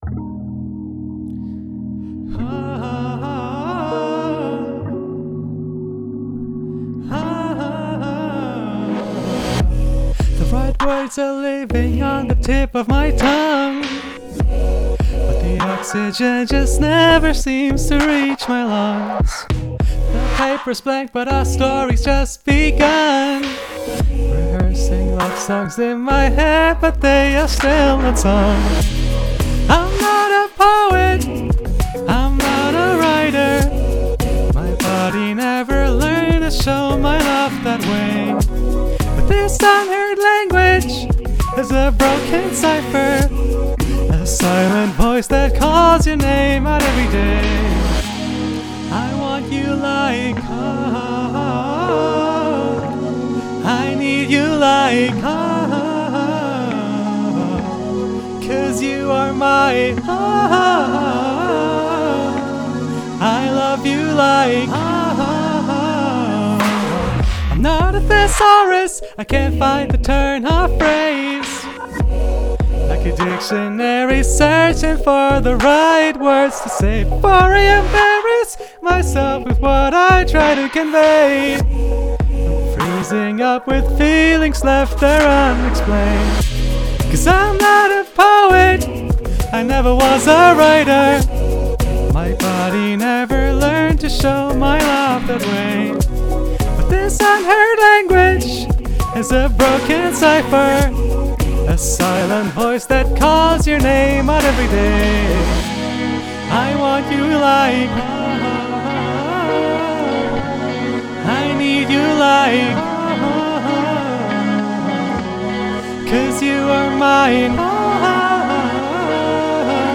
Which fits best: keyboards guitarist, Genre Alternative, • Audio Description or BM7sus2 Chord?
Genre Alternative